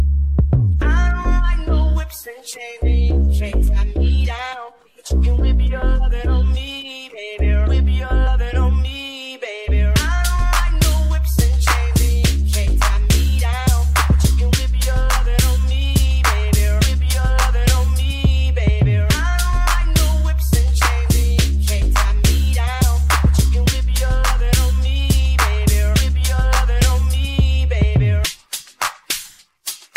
Жанр: Танцевальная музыка
# Dance